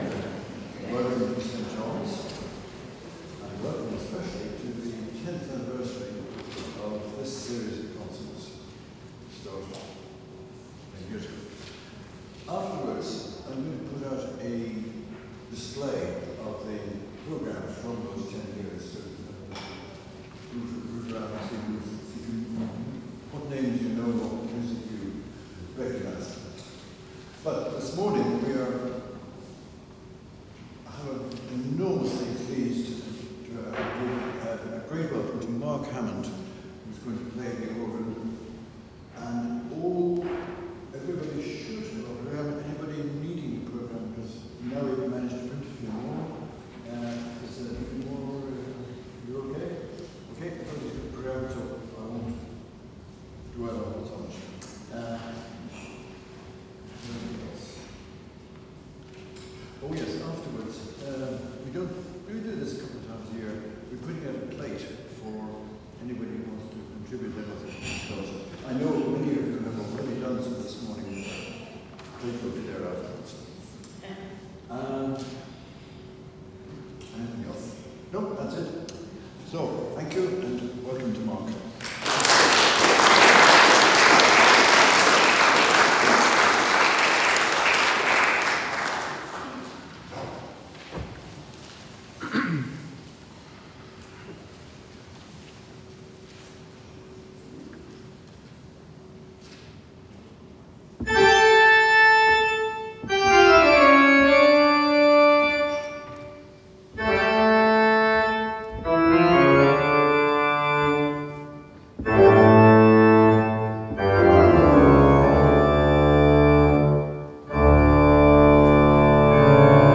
Organ Recital at St John's Church in Northwood